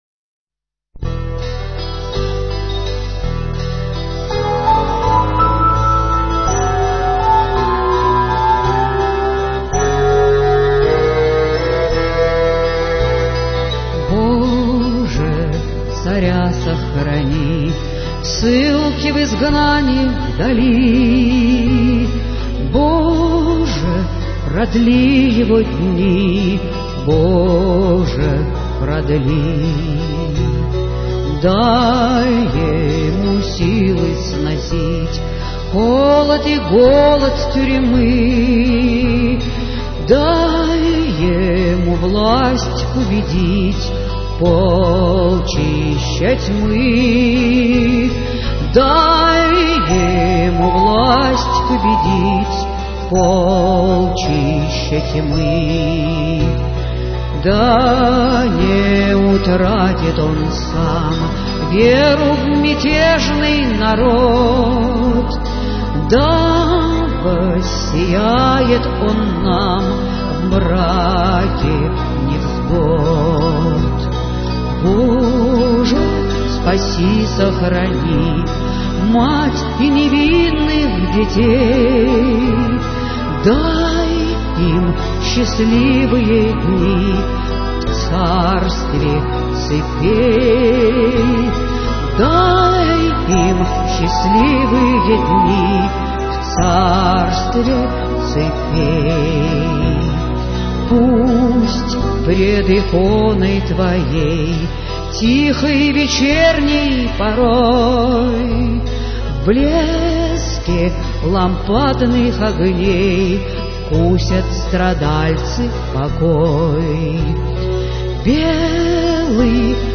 Авторская песня